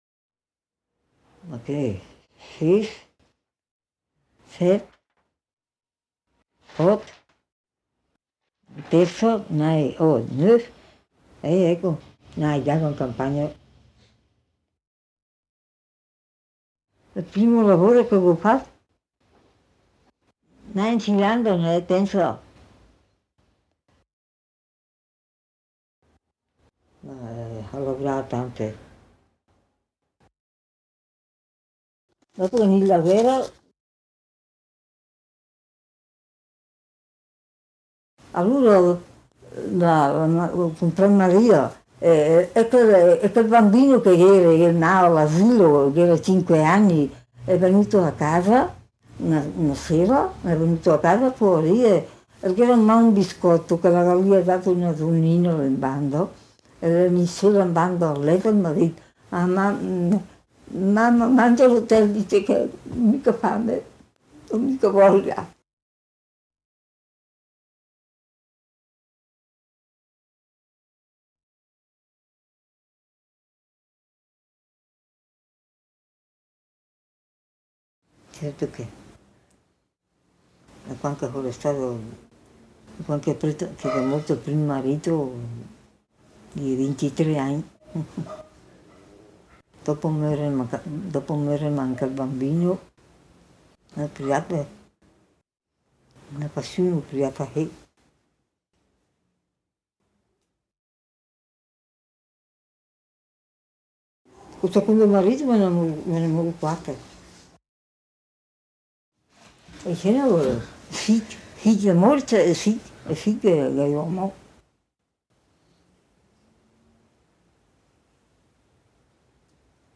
drumset / graphic scores / improvisation /